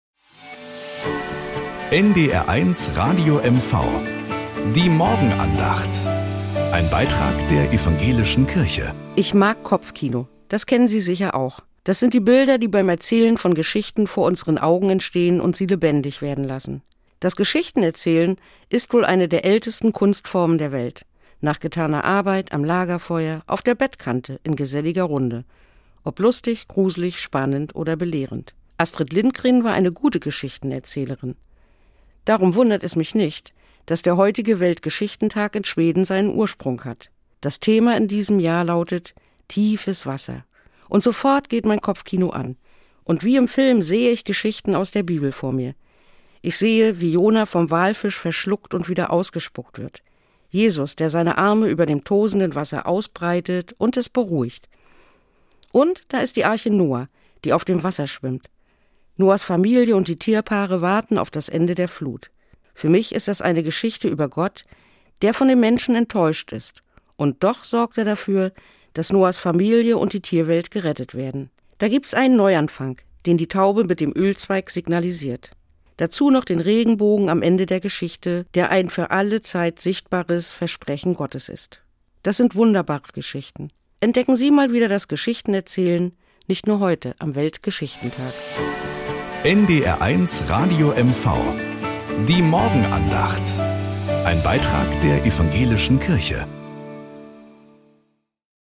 Nachrichten aus Mecklenburg-Vorpommern - 04.05.2025